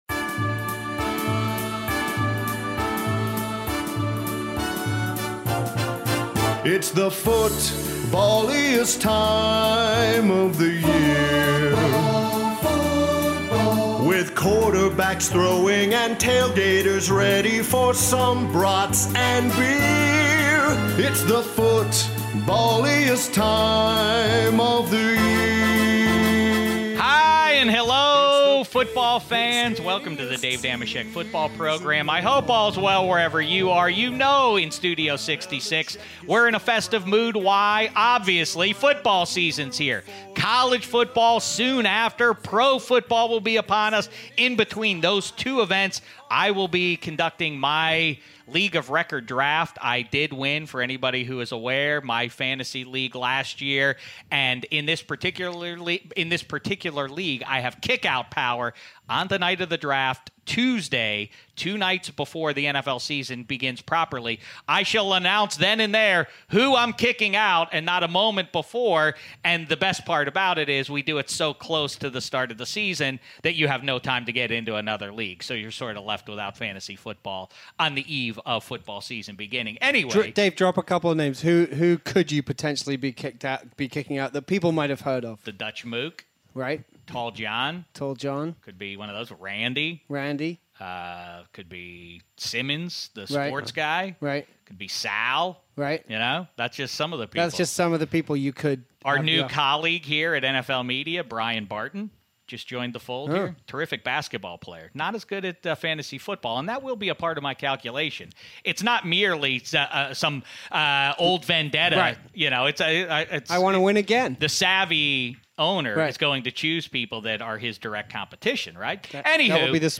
Dave Dameshek is joined in Studio 66
Skypes in to talk about this latest season of the show following the Cleveland Browns (3:19).